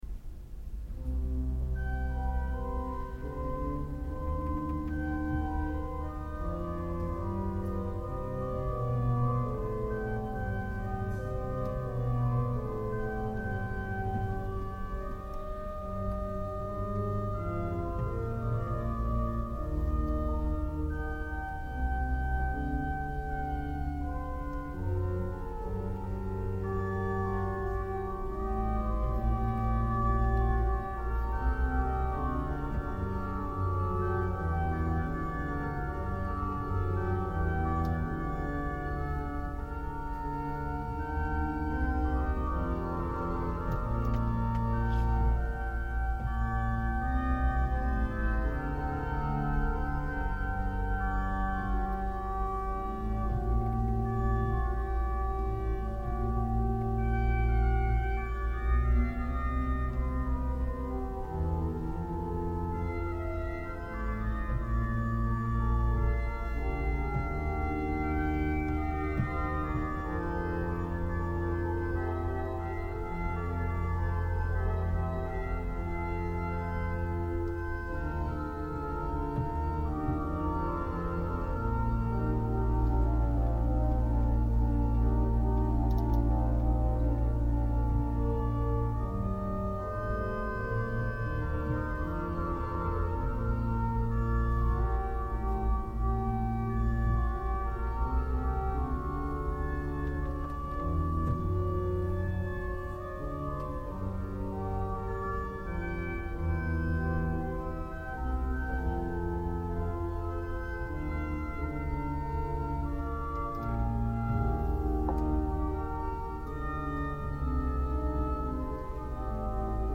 Alcuni brani registrati dal vivo durante i concerti.
Presa amatoriale effettuata con un registratore EDIROL-R09 posto fra il pubblico.